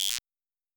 S 78_Guiro1.wav